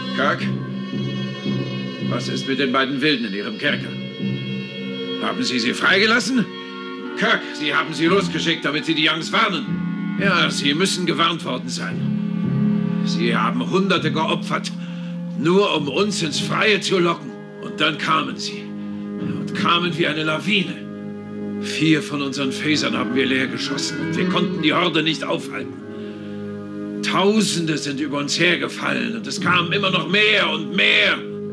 Friedrich Schoenfelder sagt als Ronald Tracey den Satz Kirk, was ist mit den beiden Wilden in ihrem Kerker?